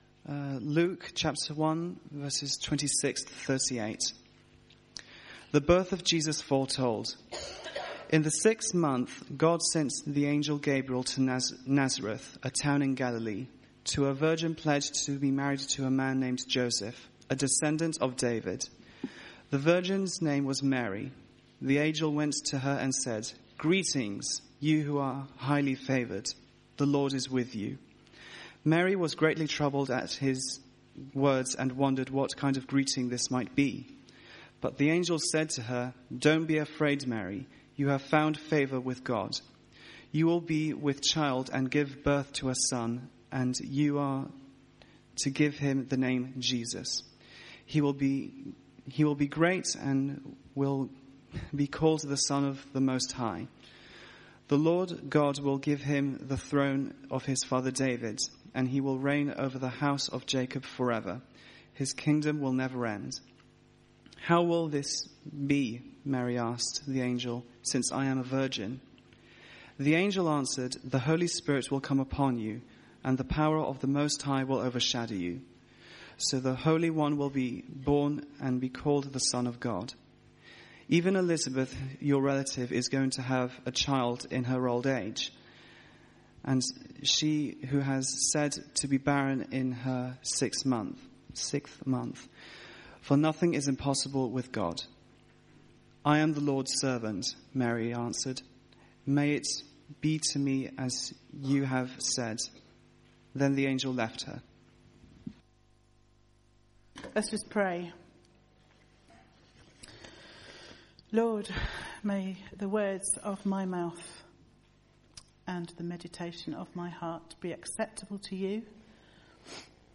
Theme: Jesus' Birth foretold Sermon